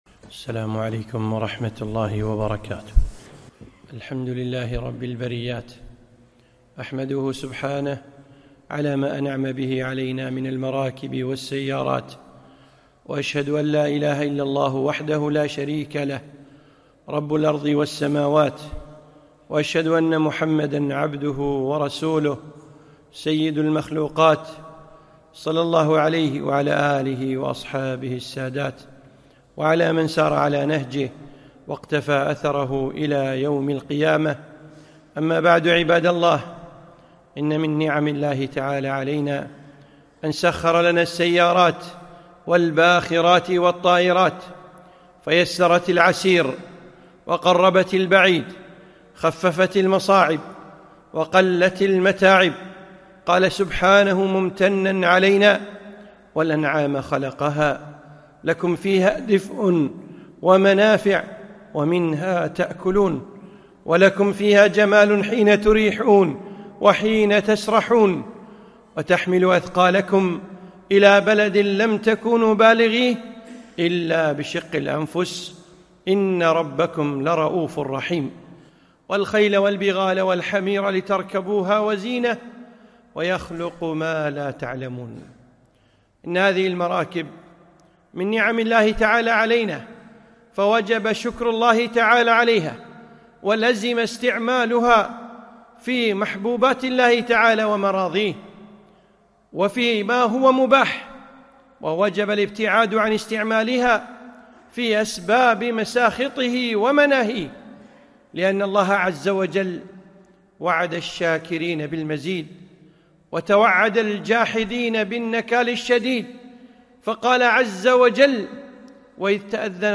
خطبة - المراكب نعمة وابتلاء